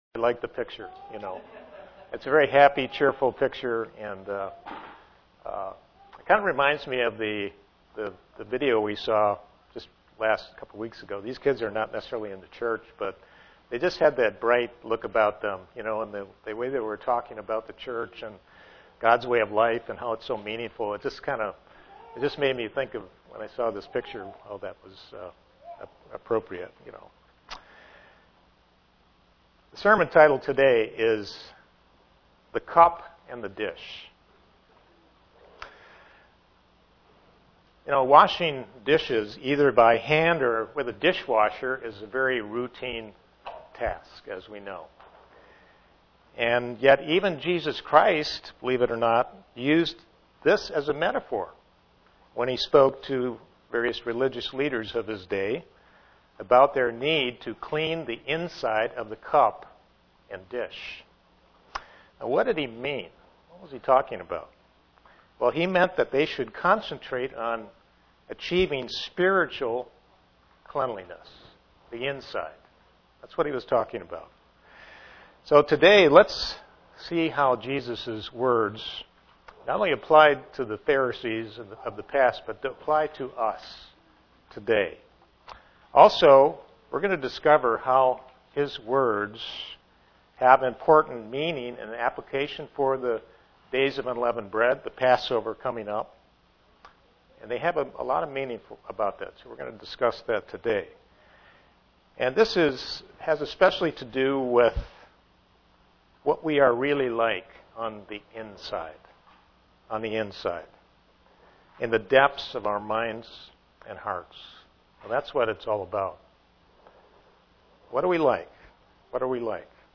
(Presented to the Knoxville TN, Church)